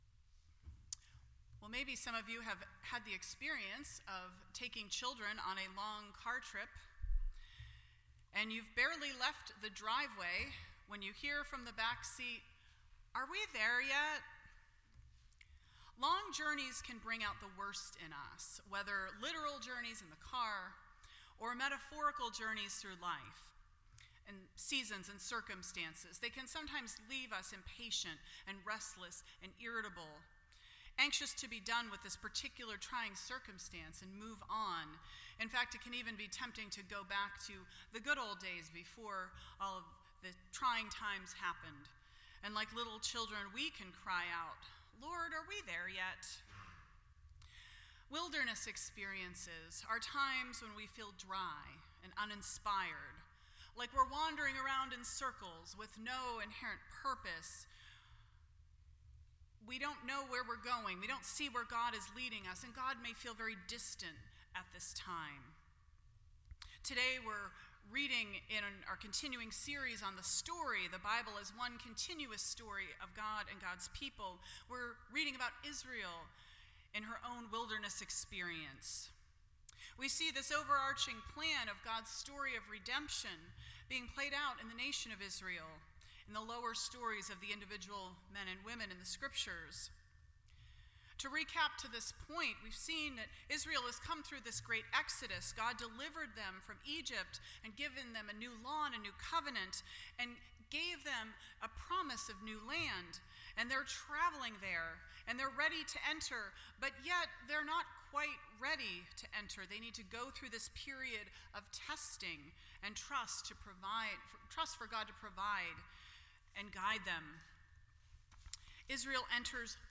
The Story Service Type: All Saints Day %todo_render% Share This Story